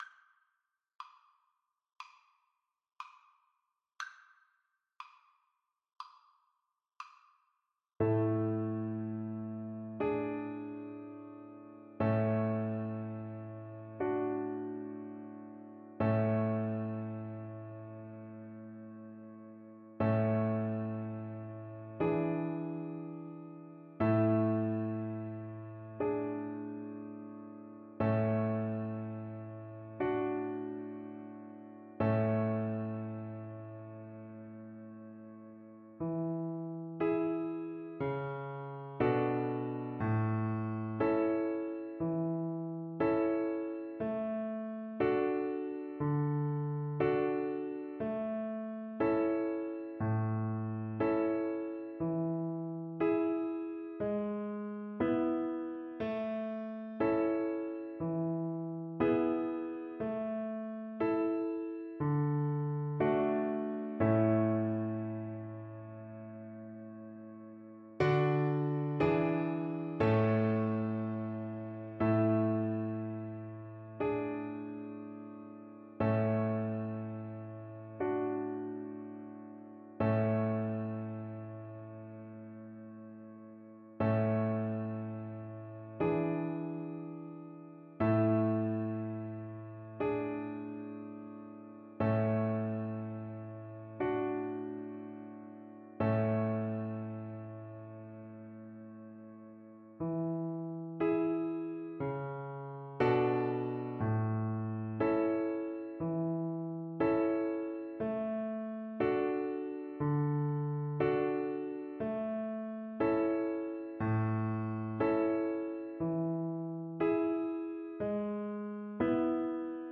Violin
4/4 (View more 4/4 Music)
A major (Sounding Pitch) (View more A major Music for Violin )
Moderato
Traditional (View more Traditional Violin Music)
Australian